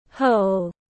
Cái hố tiếng anh gọi là hole, phiên âm tiếng anh đọc là /həʊl/.